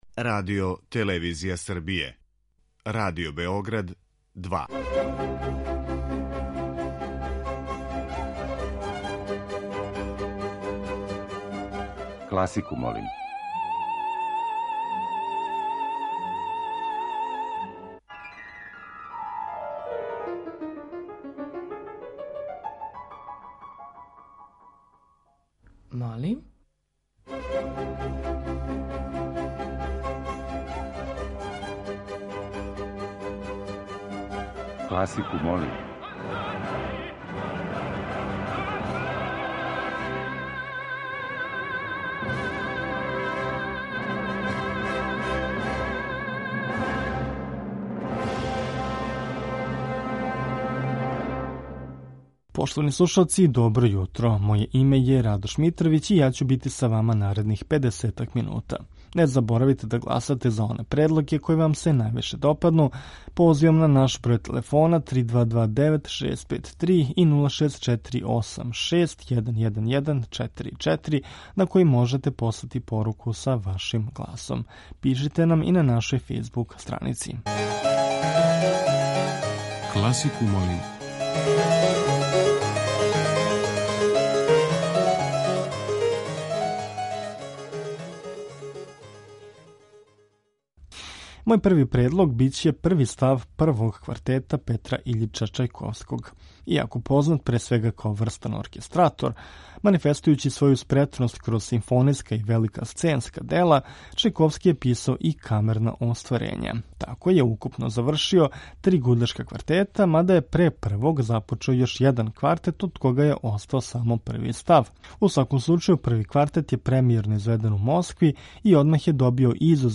Окосница овонедељне емисије Класику, молим биће дела за кларинет.